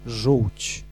Ääntäminen
Synonyymit fiel Ääntäminen France Tuntematon aksentti: IPA: /bil/ Haettu sana löytyi näillä lähdekielillä: ranska Käännös Konteksti Ääninäyte Substantiivit 1. żółć {f} anatomia Suku: f .